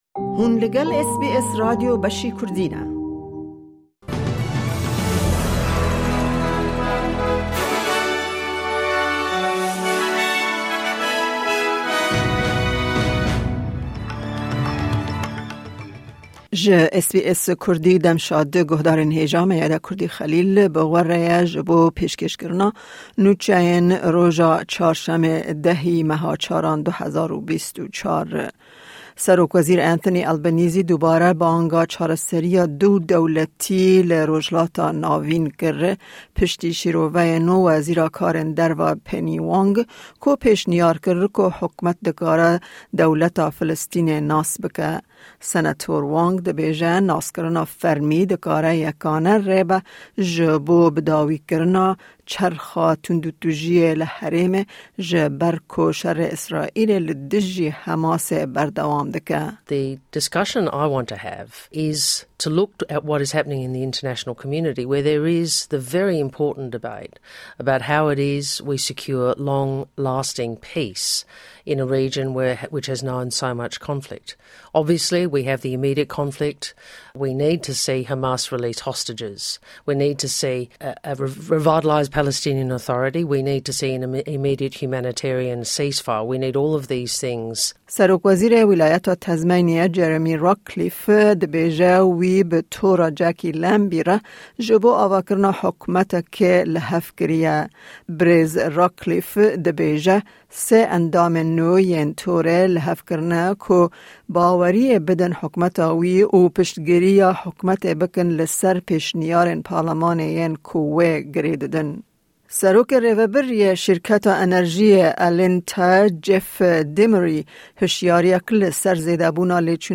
Kurte Nûçeyên roja Çarşemê 10î Nîsana 2024